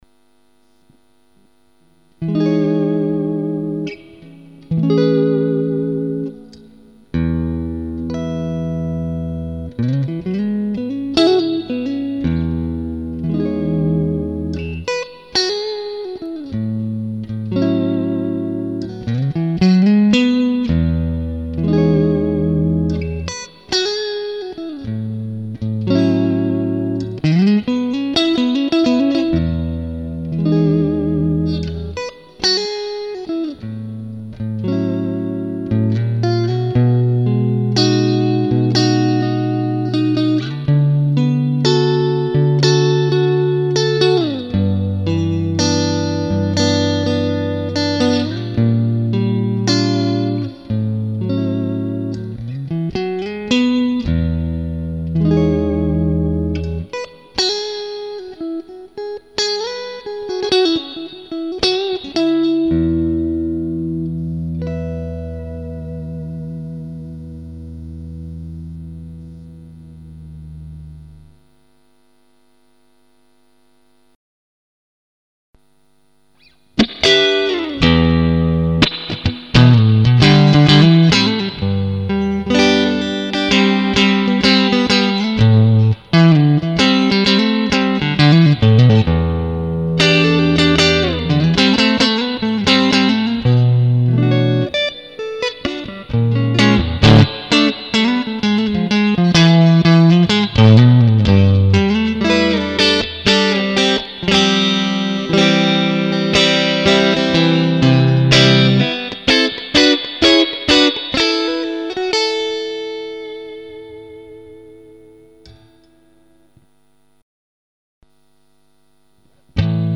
enregistré dans un mesa mark IV (combo) pas de prise de son, sortie REC OUT